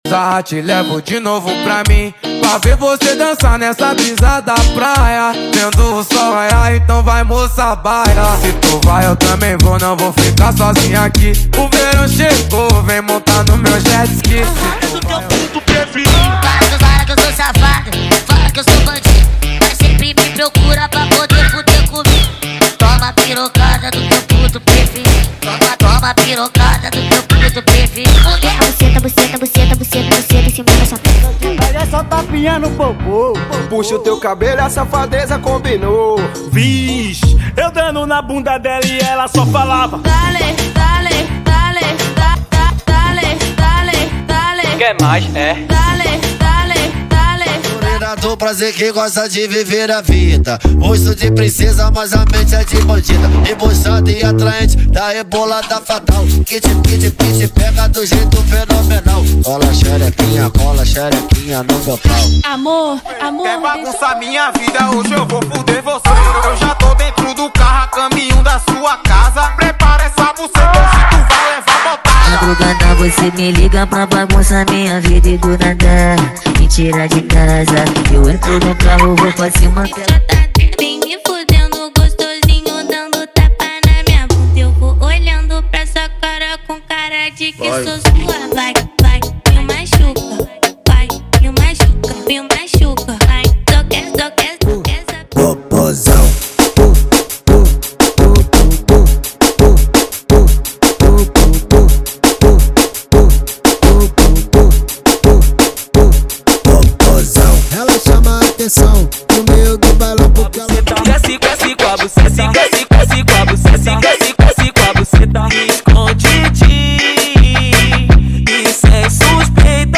Os Melhores Brega Funk do momento estão aqui!!!
• Brega Funk = 100 Músicas
• Sem Vinhetas
• Em Alta Qualidade